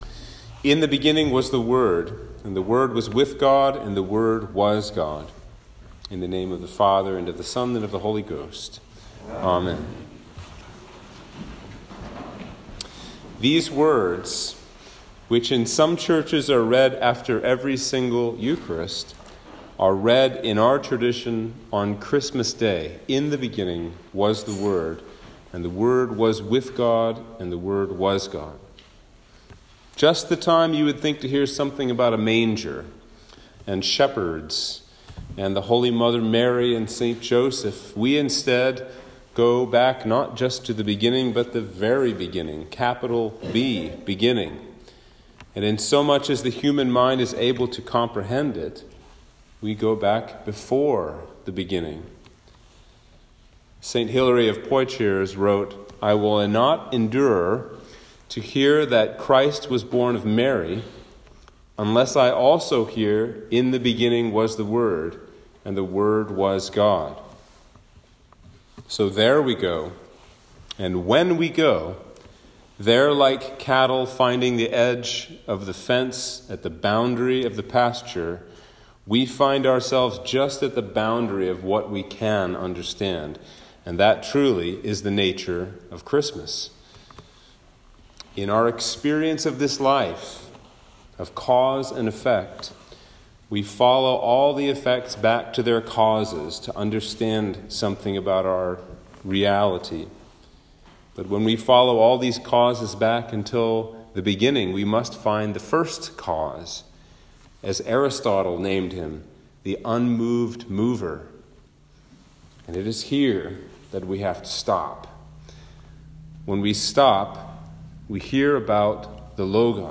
Sermon for Christmas Day - 2021